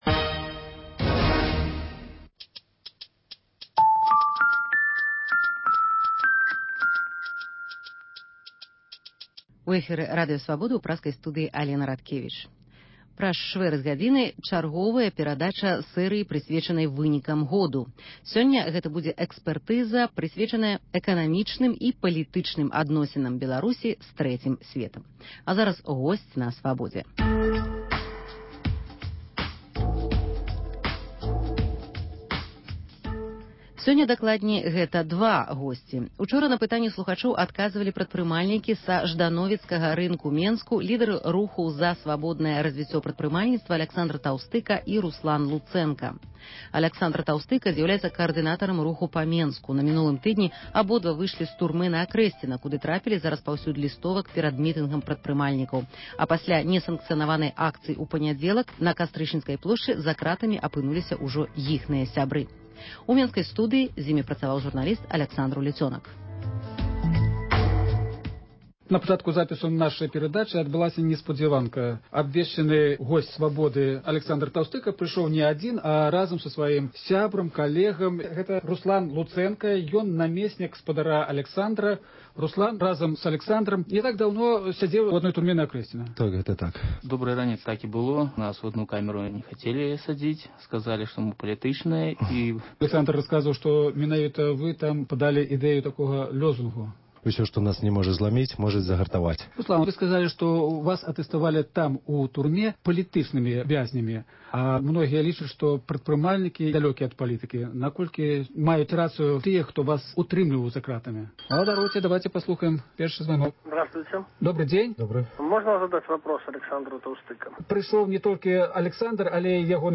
Госьць у студыі адказвае на лісты, званкі, СМС-паведамленьні.